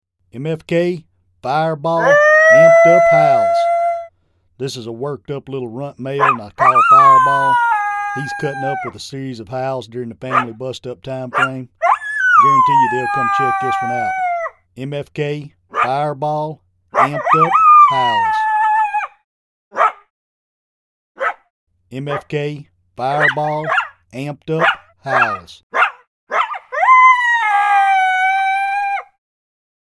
Recorded with the best professional grade audio equipment MFK strives to produce the highest
The Big Difference- Our one-of-a-kind live coyote library naturally recorded at extremely close
range from our very own hand raised, free range coyotes sets MFK apart from all other libraries. Making
MFK-Fireball-Amped-Up-Howl-VO.mp3